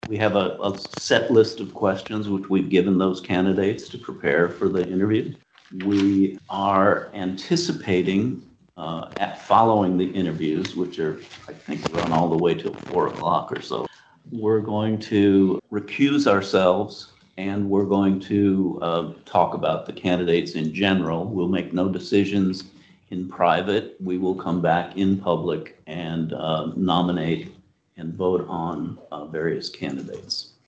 Board President Phil Giuntoli announced at an OMC board meeting this week, the interviews will take place during a special meeting this Wednesday at 1 p.m.